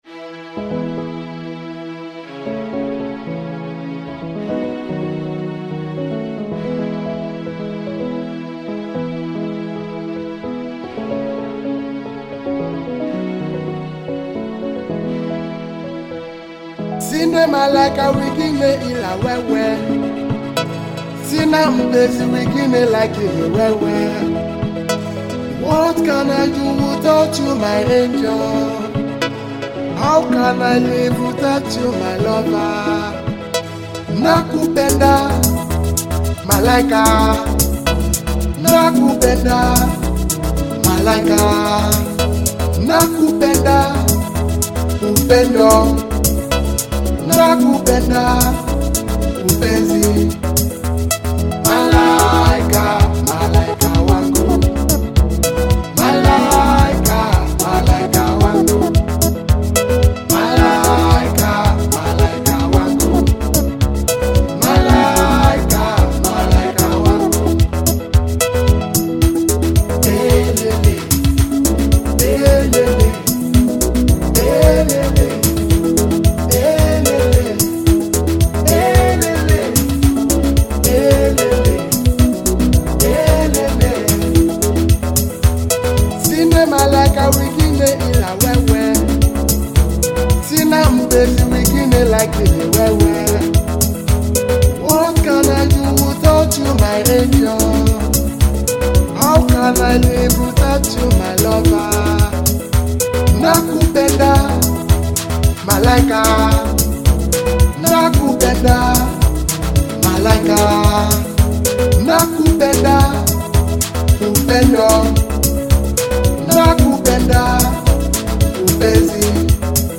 smooth vocals
blends Afrobeat with contemporary sounds
With its high energy tempo and catchy sounds